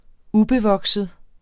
Udtale [ ˈubeˌvʌgsəð ]